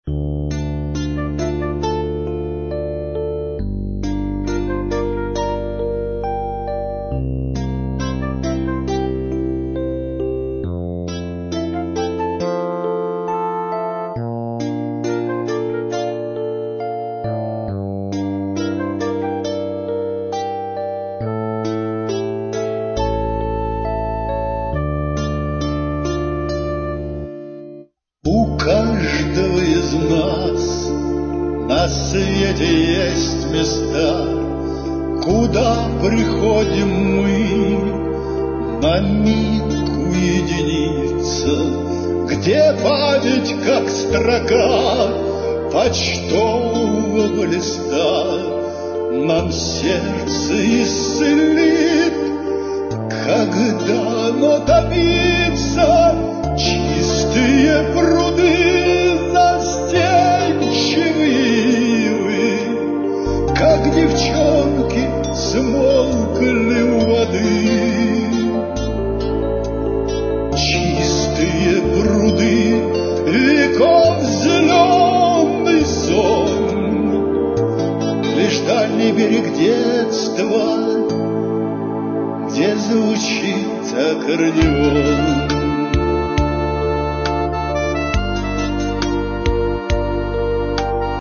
Шансон (3914)